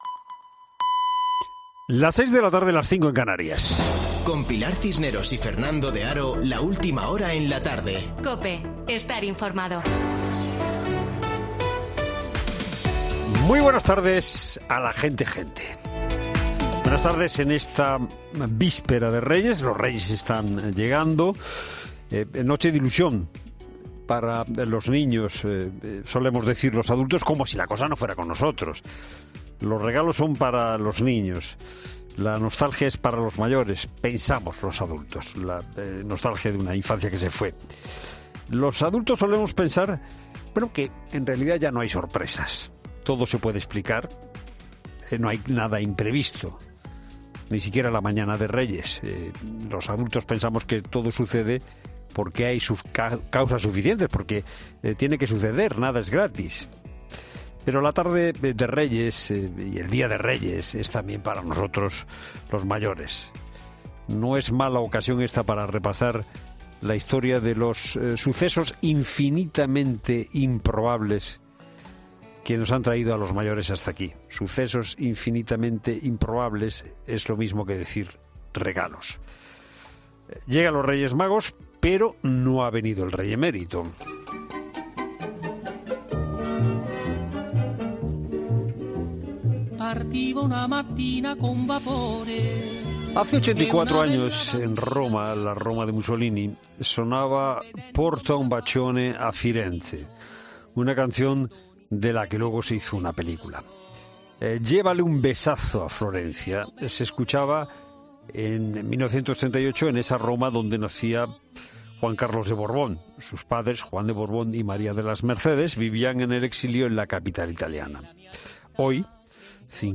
Boletín de noticias COPE del 4 de enero de 2022 a las 18:00 horas